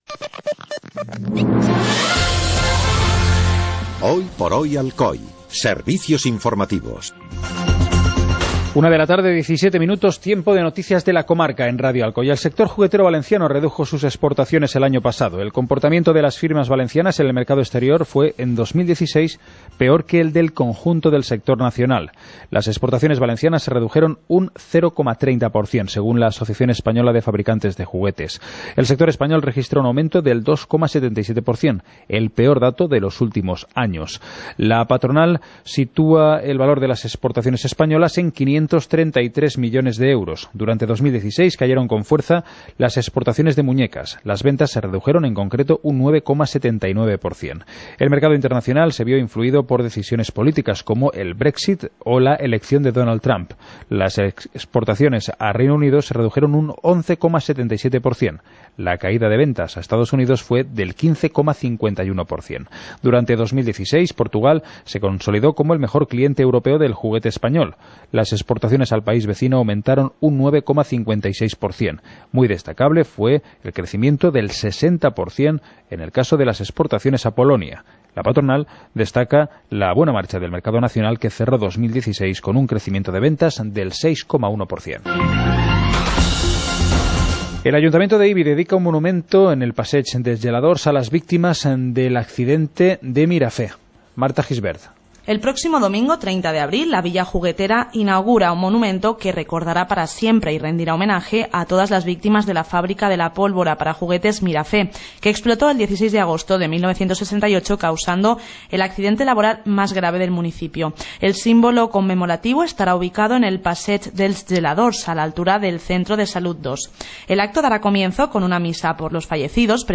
Informativo comarcal - jueves, 27 de abril de 2017